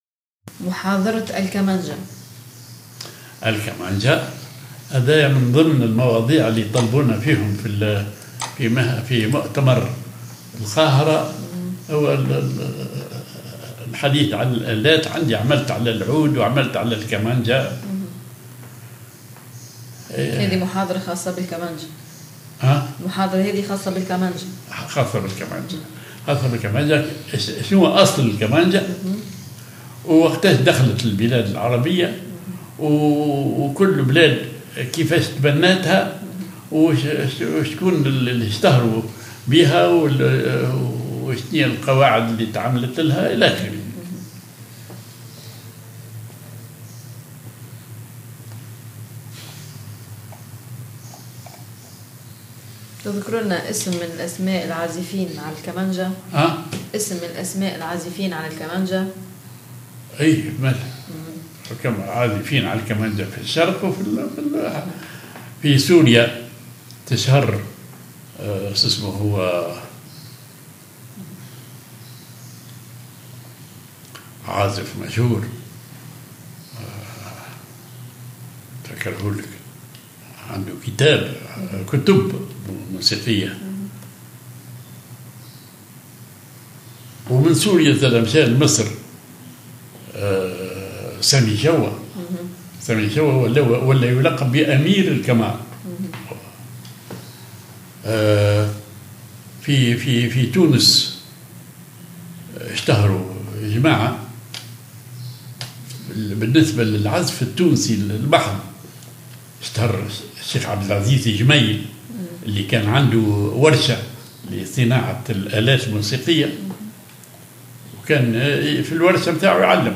violoncelle